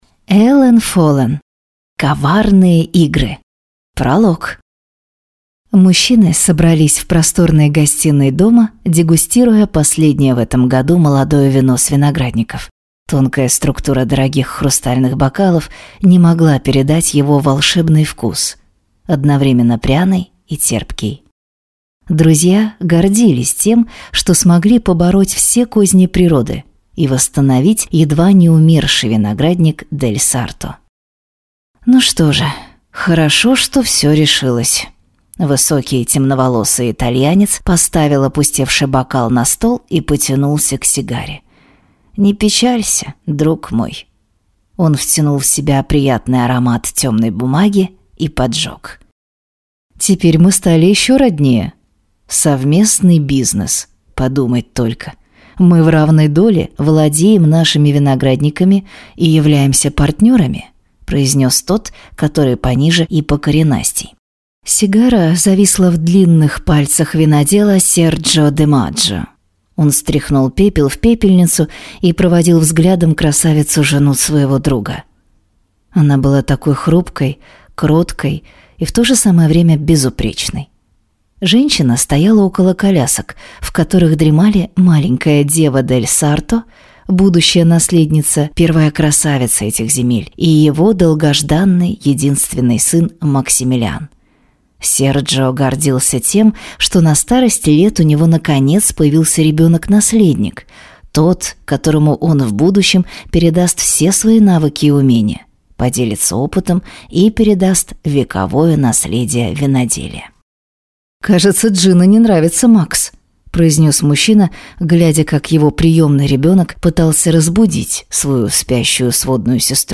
Аудиокнига Коварные игры | Библиотека аудиокниг